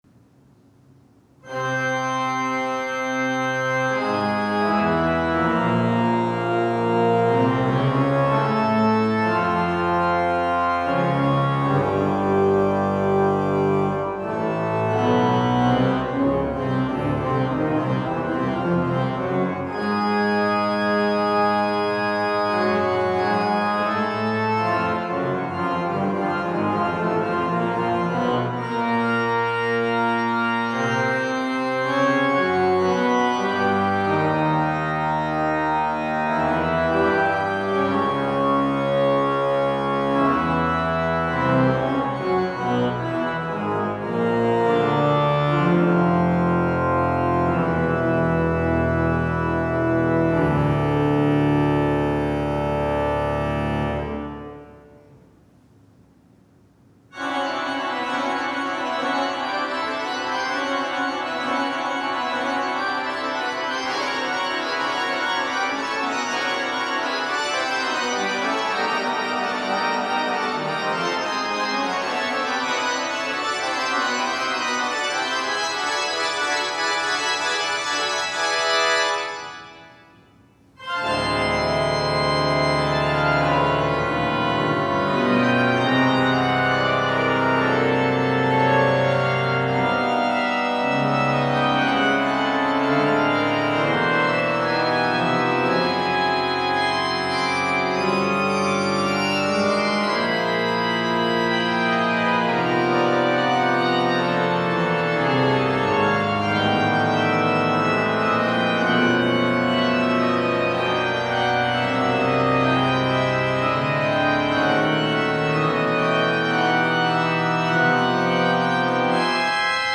Genre: Solo Organ
Solo Organ